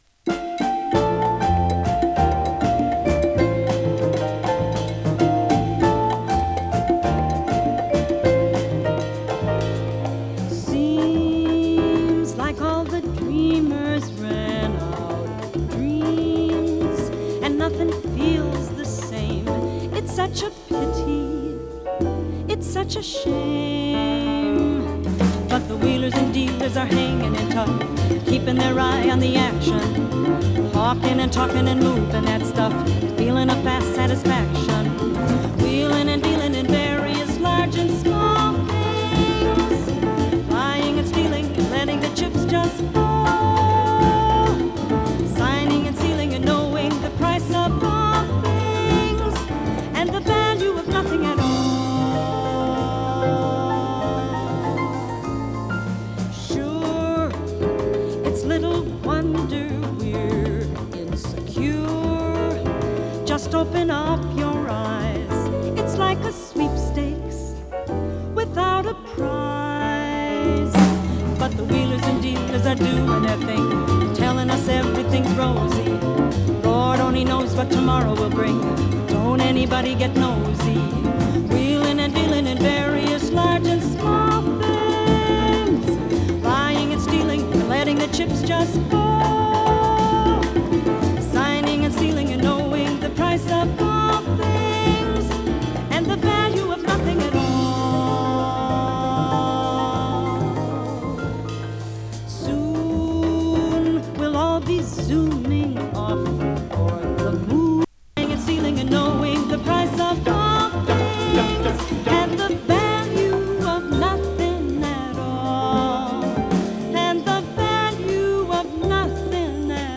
＊時おり軽いチリ/パチ・ノイズ。